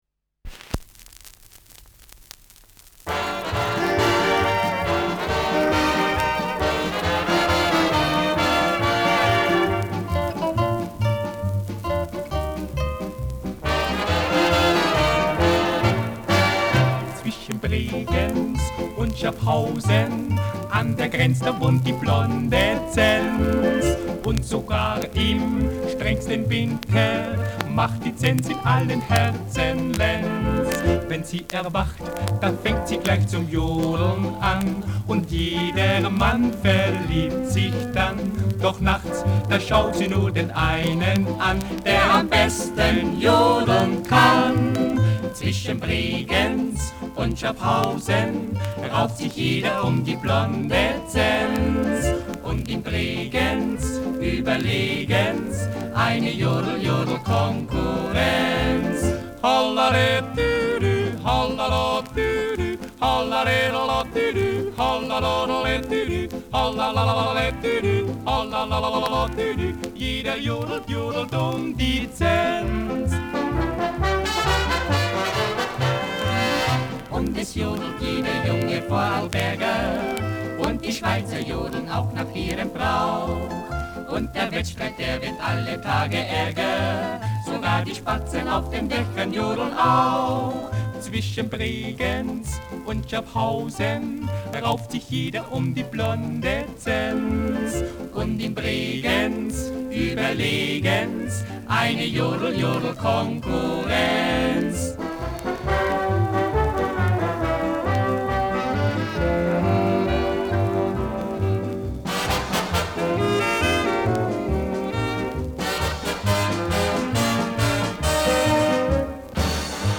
Schellackplatte
gelegentliches Knistern
[Köln] (Aufnahmeort)